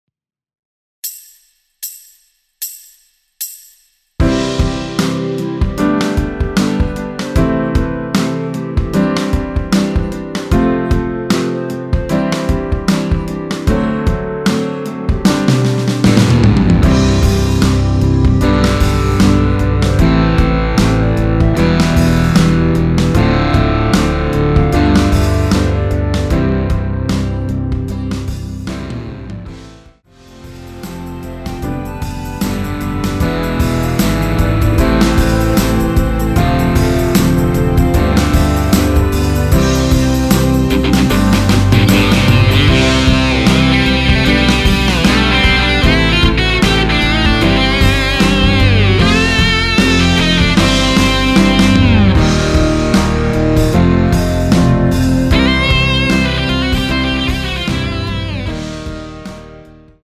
원키에서(-5)내린 (2절 삭제) 멜로디 포함된 MR입니다.
앞부분30초, 뒷부분30초씩 편집해서 올려 드리고 있습니다.
중간에 음이 끈어지고 다시 나오는 이유는